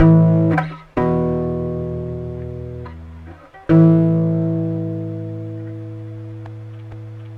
权力和弦
描述：FL，原声通过等化和失真，DM为原声。
Tag: 130 bpm Rock Loops Guitar Electric Loops 1.24 MB wav Key : Unknown FL Studio